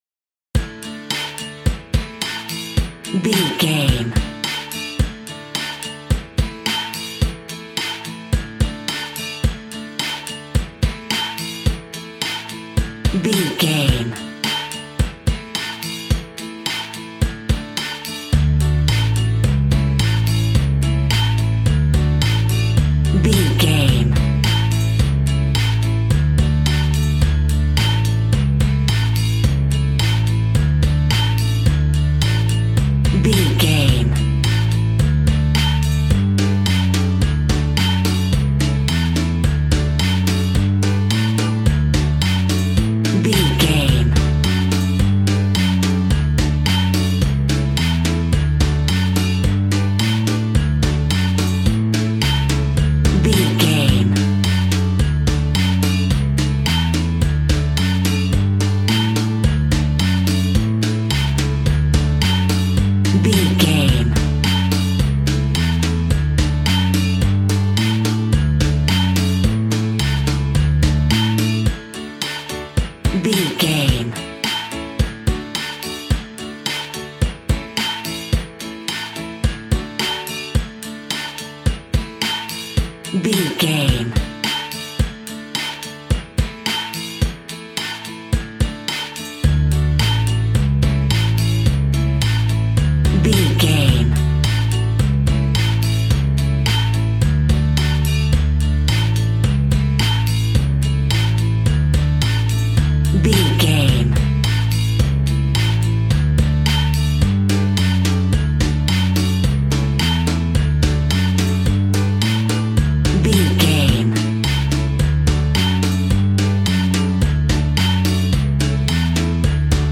Ionian/Major
D
Fast
childrens music
instrumentals
kids library music
fun
childlike
cute
kids piano